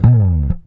Slide Down (JW3).wav